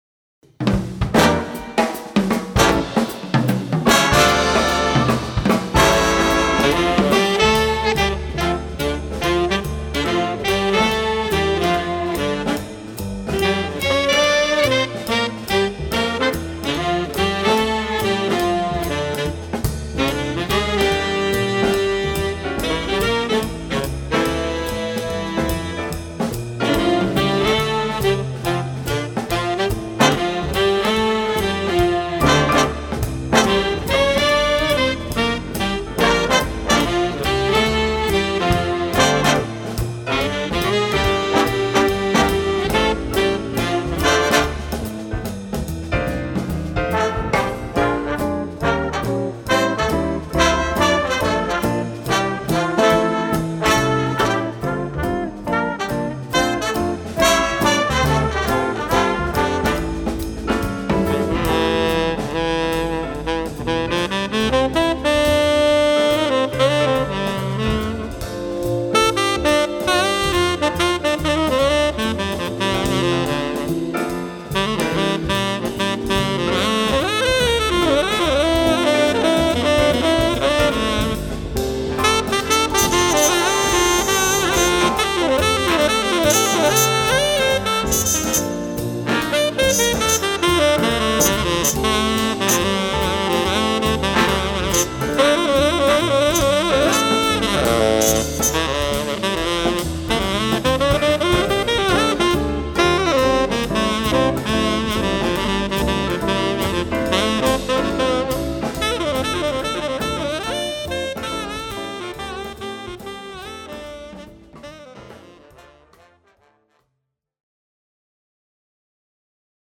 3. Big Band Realization